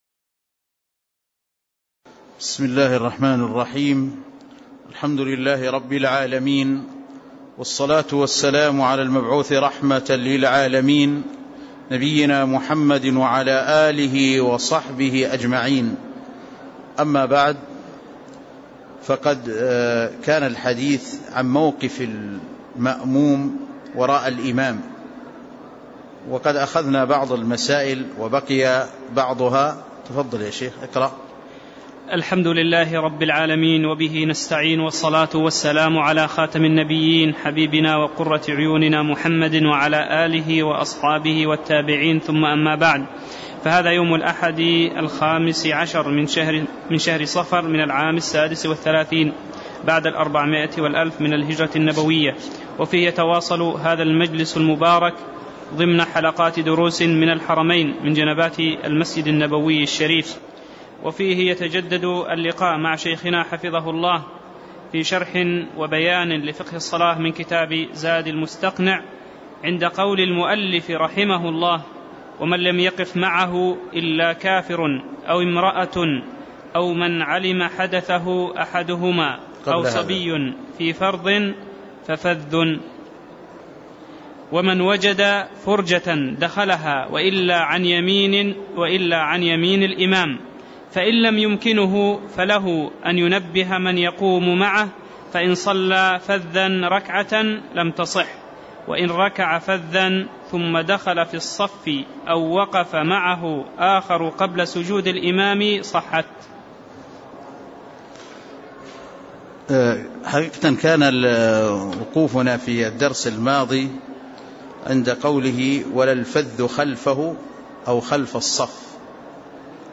تاريخ النشر ١٥ صفر ١٤٣٦ هـ المكان: المسجد النبوي الشيخ